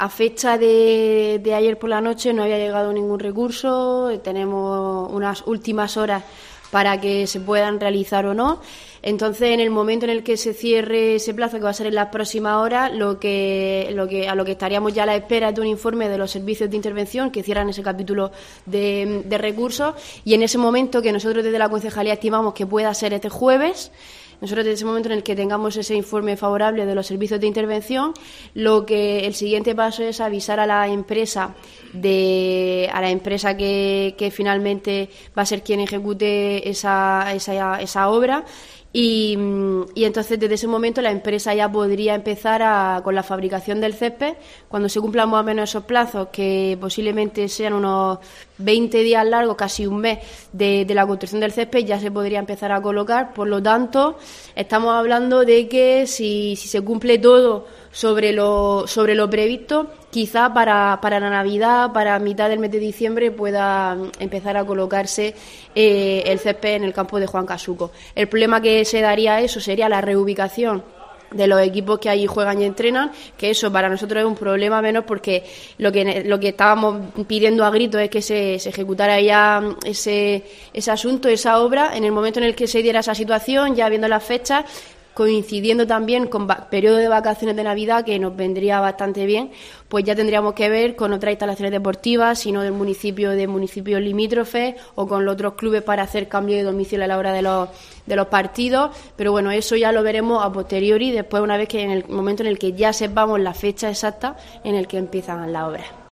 Irene Jódar, edil del Deportes, sobre el Juan Casuco